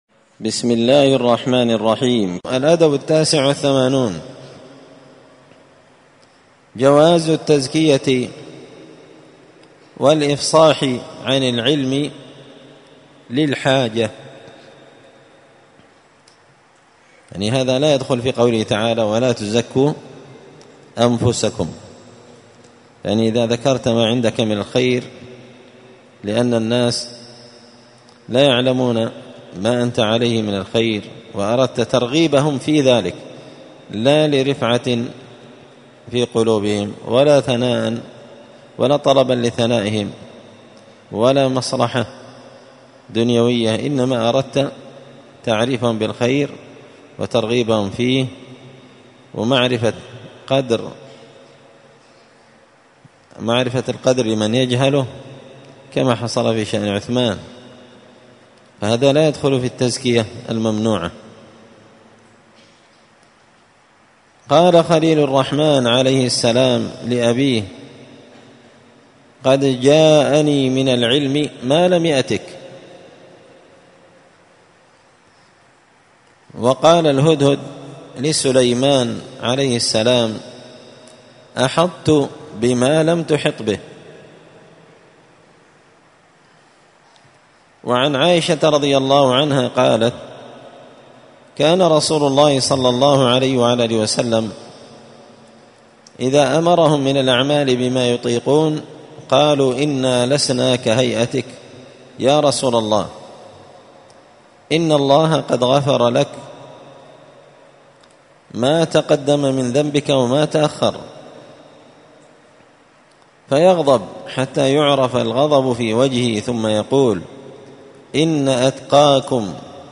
*الدرس التاسع والتسعون (99) الأدب التاسع والثمانون جواز التزكية والإفصاح عن العلم للحاجة*
مسجد الفرقان قشن_المهرة_اليمن